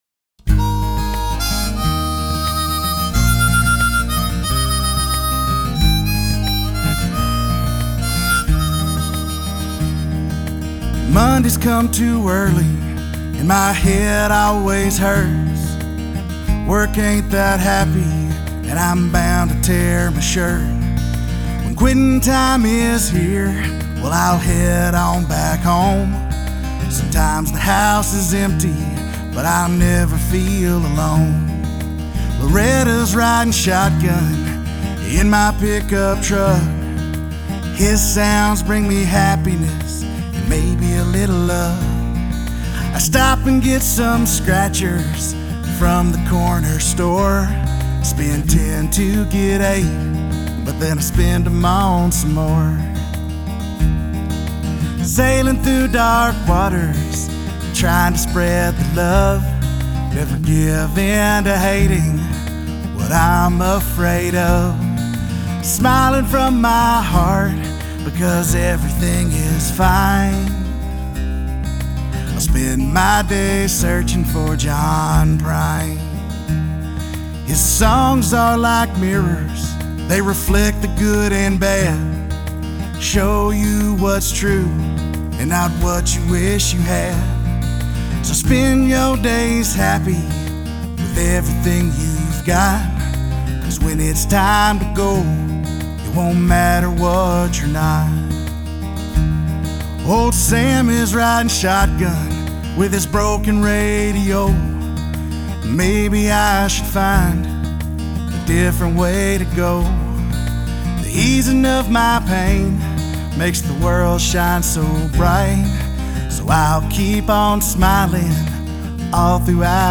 "Searching For John Prine" (folk/Americana)